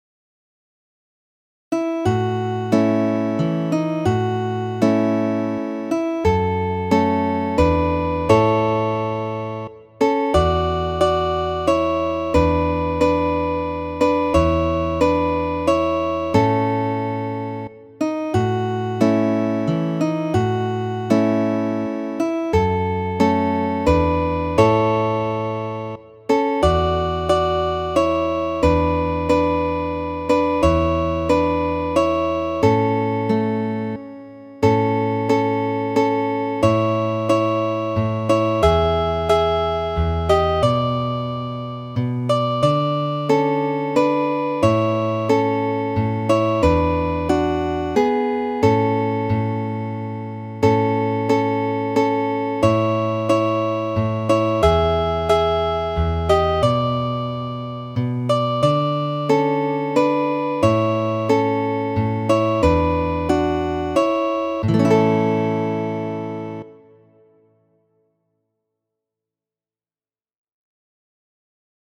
Kajero 48 ~ PDF (paĝo ª) ~ Kajeroj Muziko: Poemojn verkante , anonima kanto.